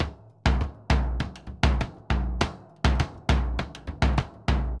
Bombo_Salsa 100_3.wav